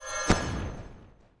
Uncanny Sound
神秘的声音